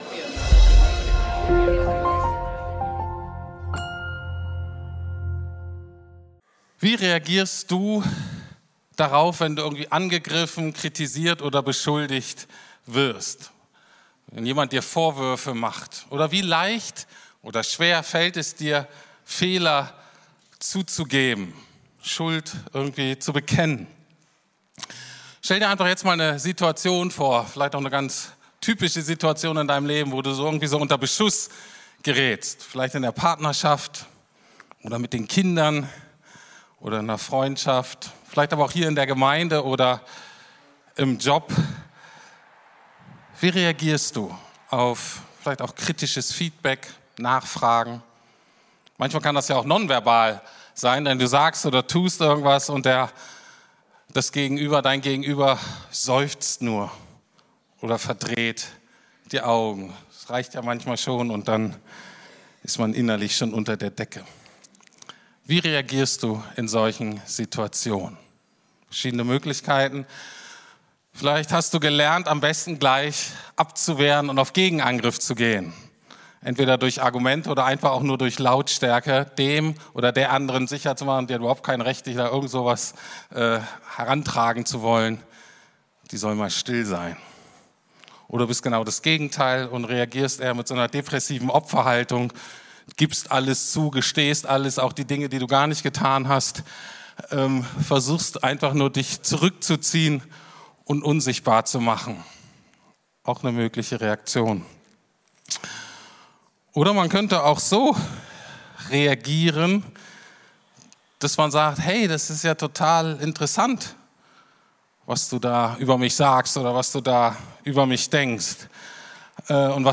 Gerecht! Fertig! ~ Predigten der LUKAS GEMEINDE Podcast